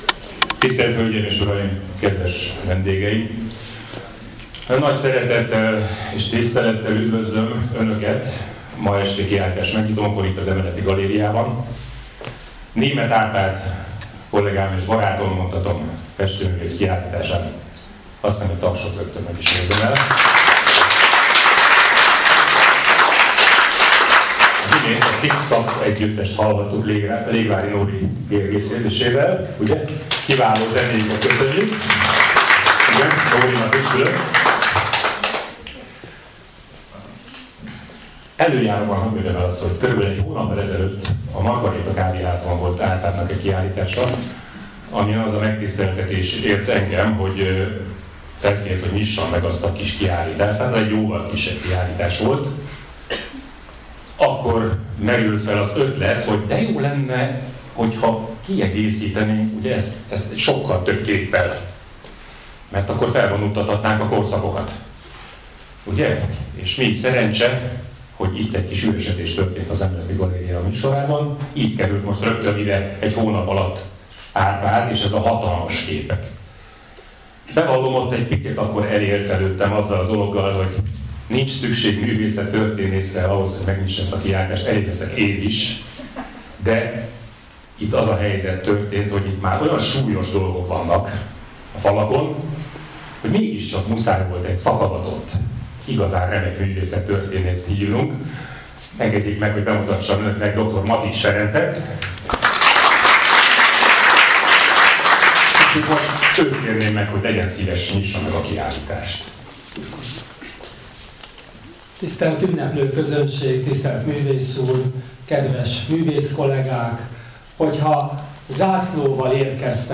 Az elhangzott köszöntõk (diktafonosa) hangfelvétele itt meghallgatható (mp3)